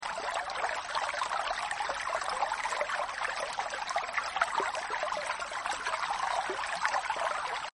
Sóng Âm thanh Alpha đến sound effects free download
Sóng Âm thanh Alpha đến từ Thế giới tự nhiên,. Giúp giấc ngủ ngon hơn.